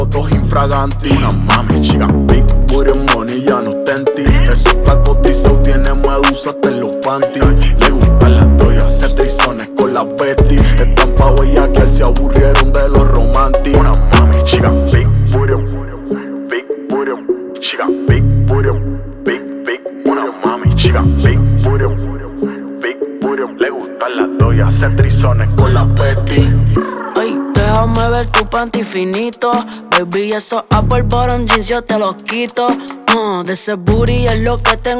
Жанр: Латиноамериканская музыка